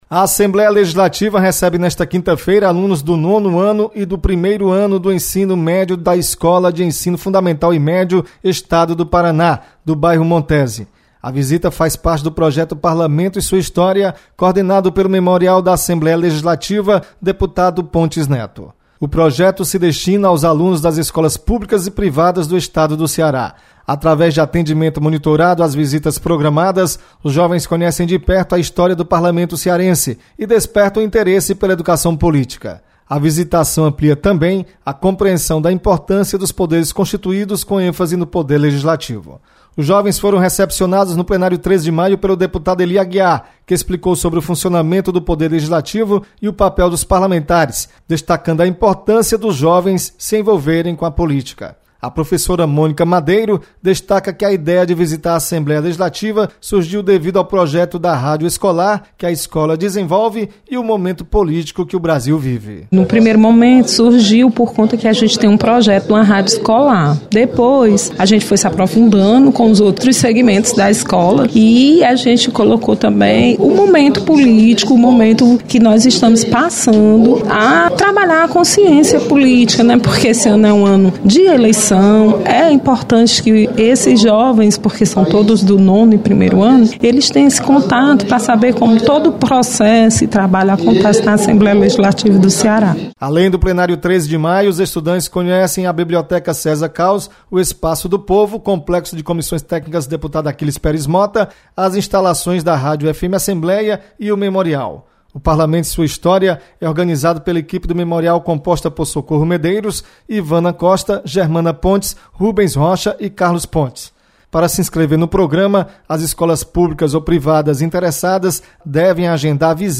Você está aqui: Início Comunicação Rádio FM Assembleia Notícias Memorial